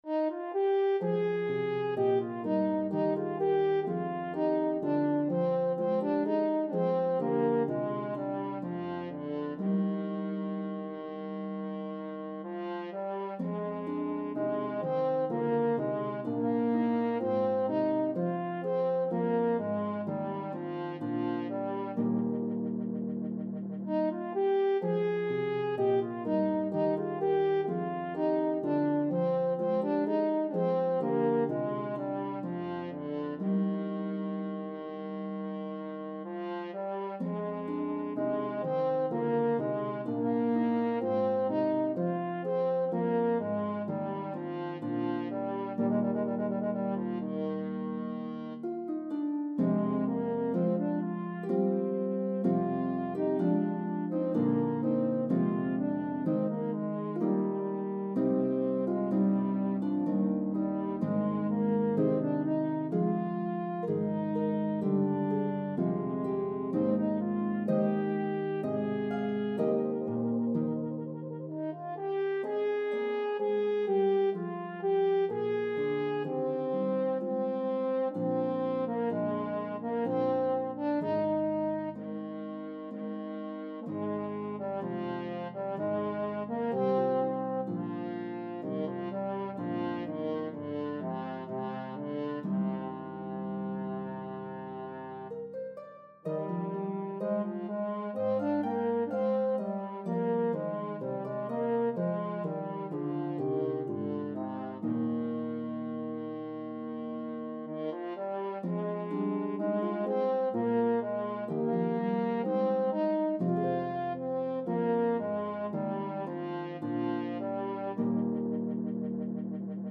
Italian Baroque style
lovely slow air